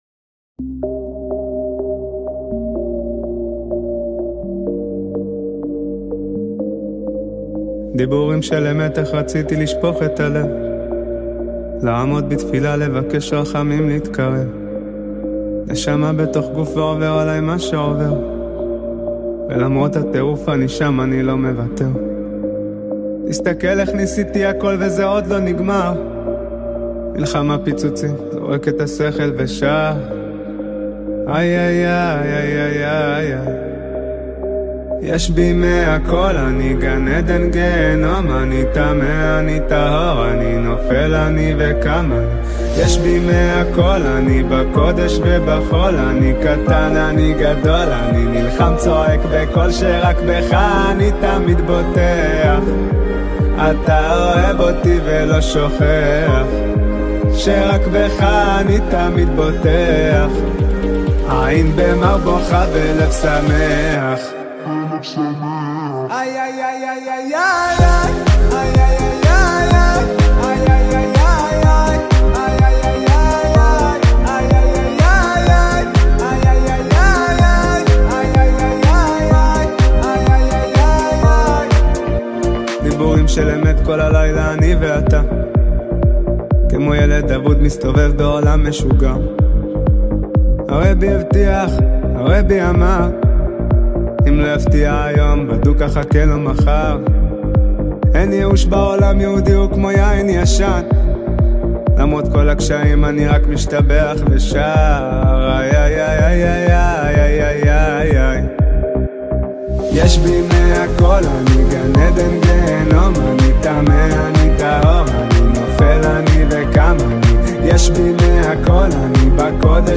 דא עקא, שמתברר לכאורה שזה AI נקי, ויוטיוב הסירה את השיר.